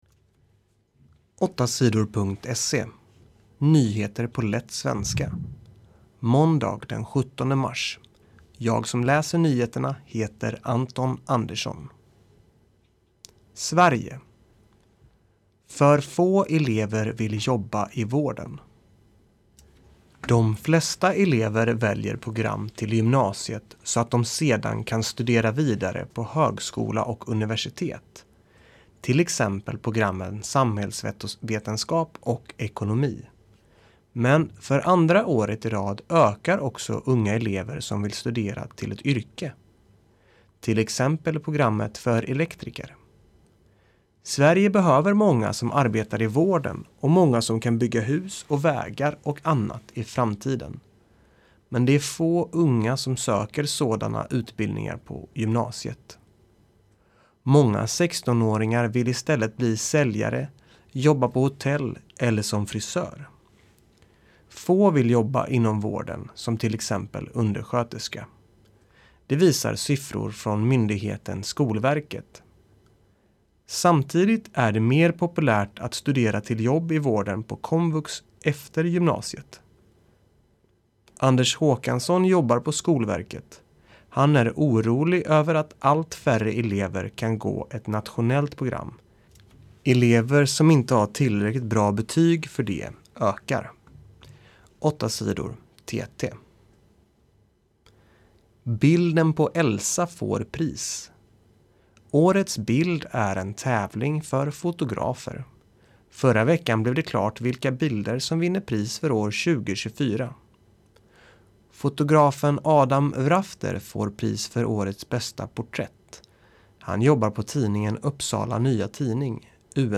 1 Nyheter på lätt svenska den 17 mars 9:32